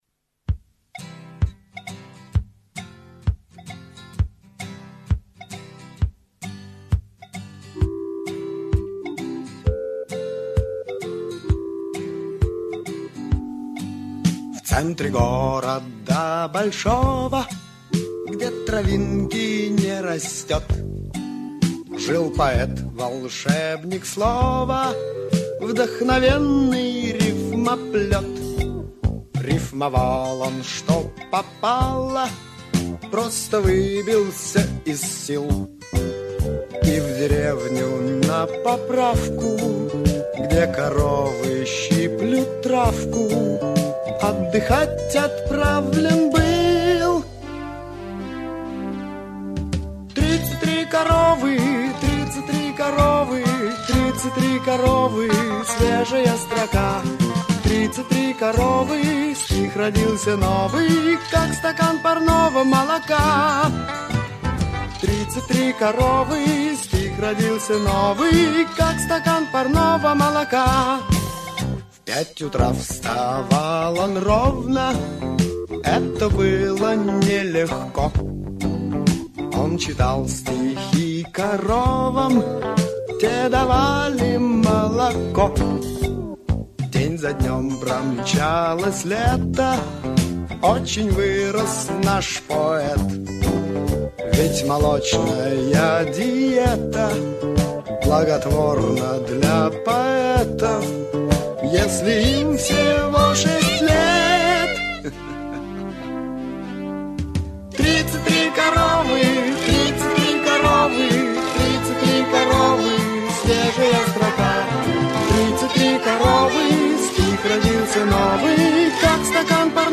Шуточная песня